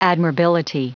Prononciation du mot admirability en anglais (fichier audio)
Prononciation du mot : admirability